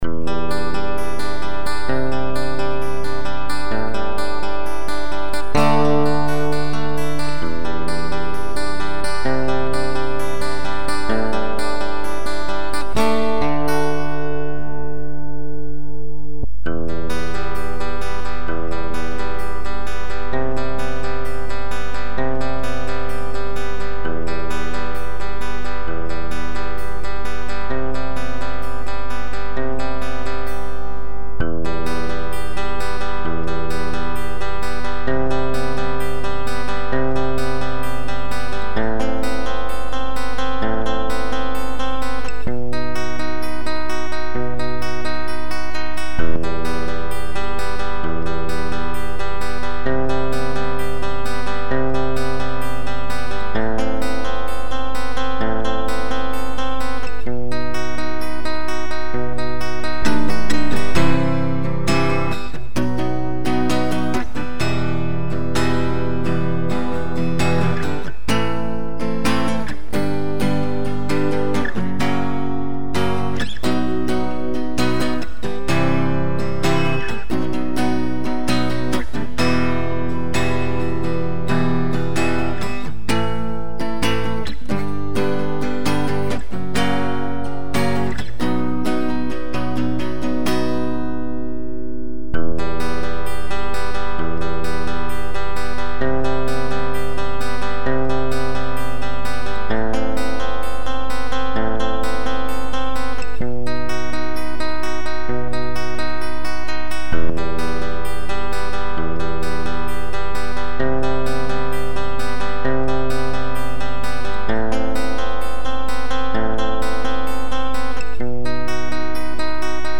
Пробач (acoustic).mp3